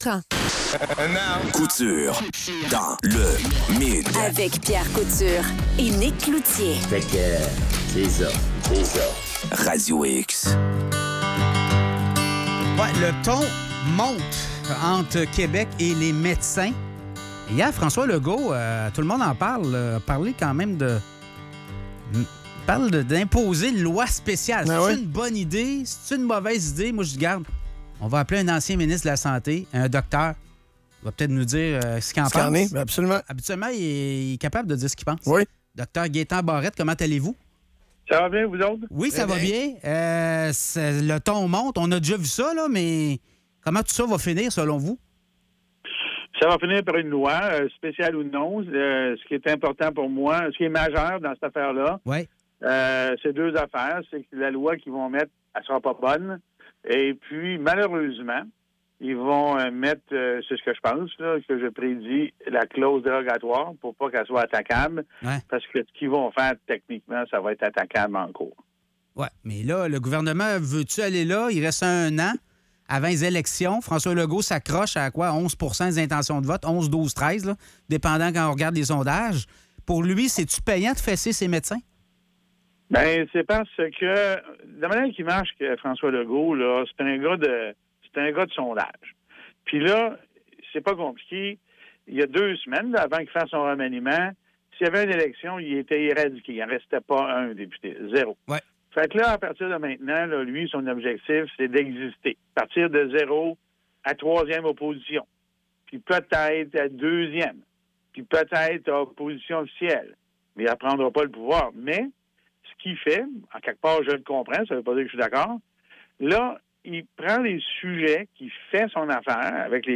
Entrevue avec Dr. Gaétan Barrette